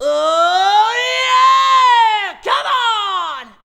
OOYEAHCOME.wav